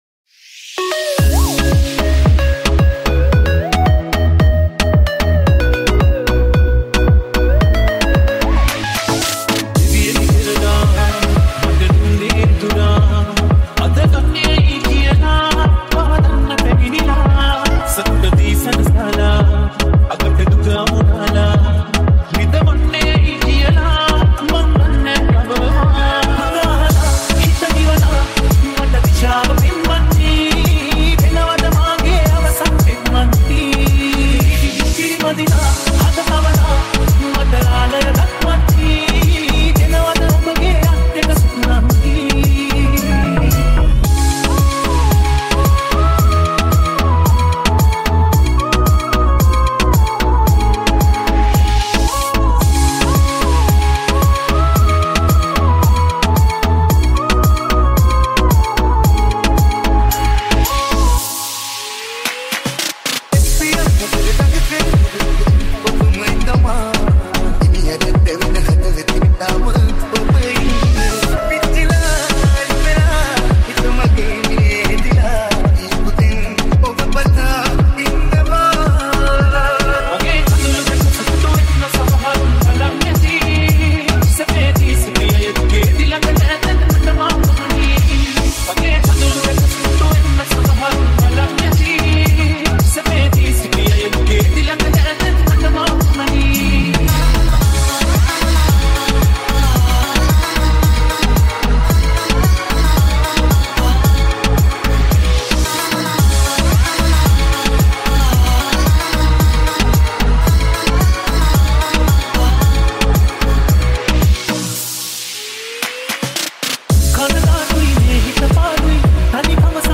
Sinhala Mashup
Sinhala DJ Song Remix